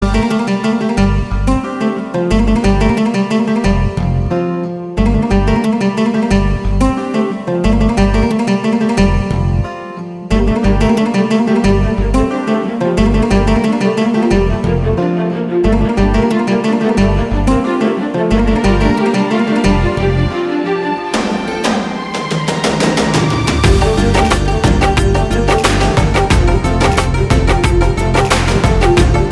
BGM Instrumental Ringtone